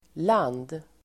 Uttal: [lan:d]